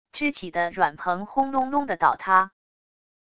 支起的软蓬轰隆隆的倒塌wav音频